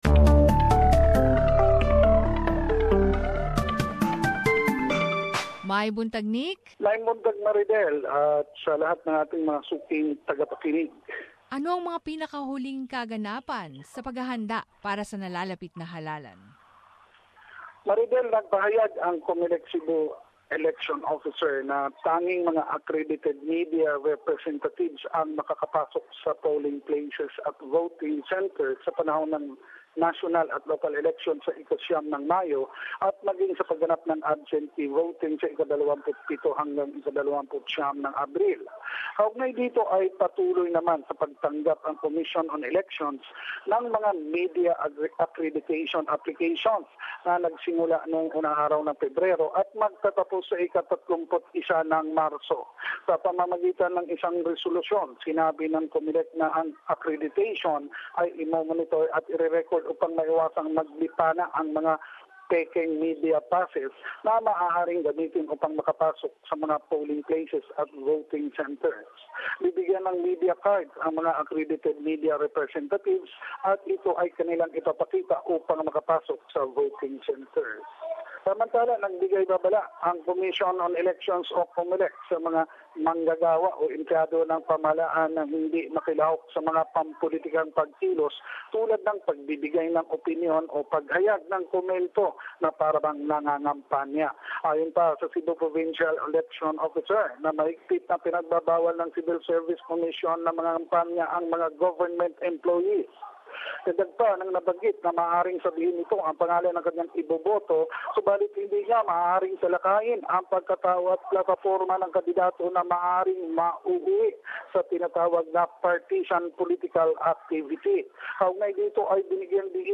Balitang Bisayas. Summary of latest relevant news in the region